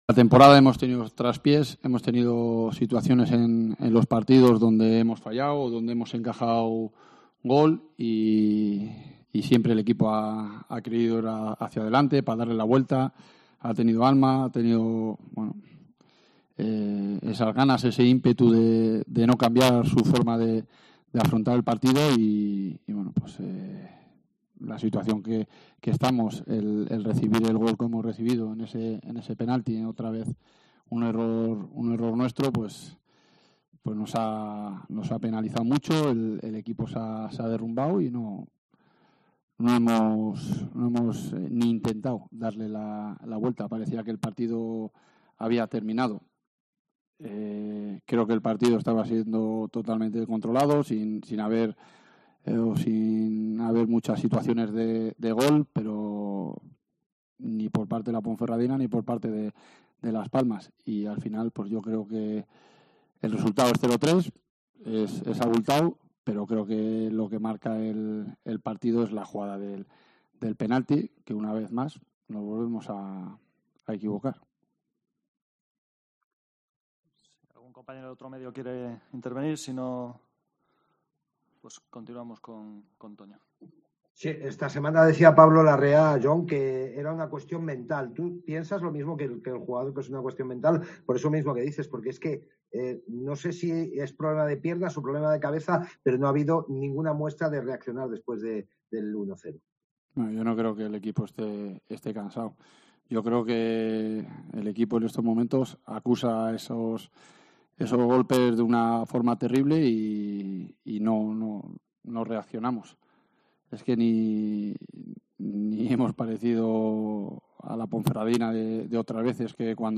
POSTPARTIDO SEGUNDA A J.38
Escucha aquí al entrenador de la Deportiva Ponferradina tras la derrota 3-0 en el estadio de Gran Canaria ante la UD Las Palmas